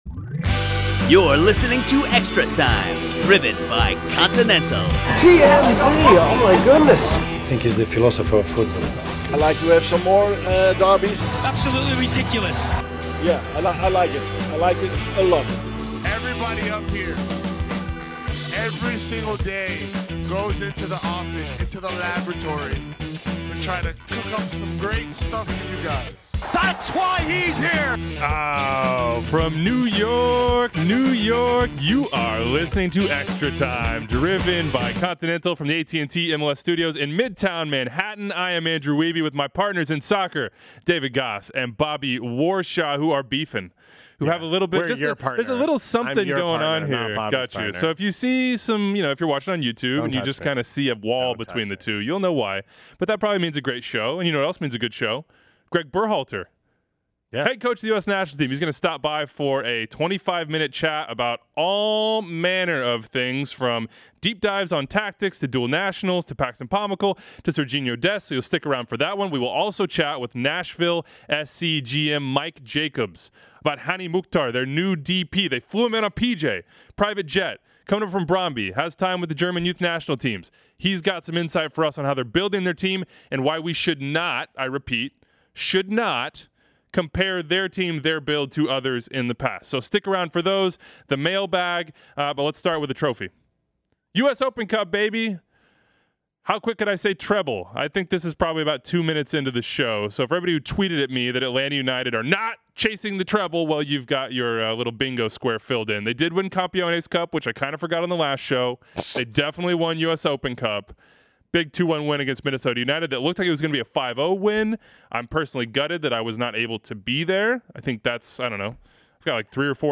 Must-listen Gregg Berhalter interview! Talking Pulisic, Pomykal, Dest, dual nats & more